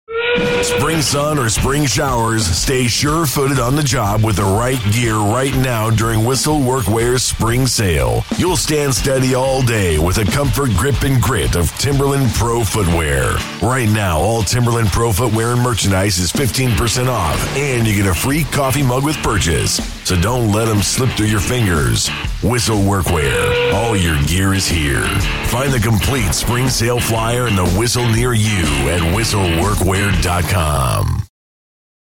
Retail Store Ad Sample